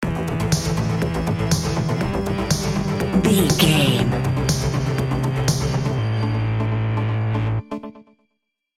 Pop Electronic Dance Music Stinger.
Fast paced
Aeolian/Minor
Fast
groovy
uplifting
energetic
bouncy
synthesiser
drum machine
house
synth bass
upbeat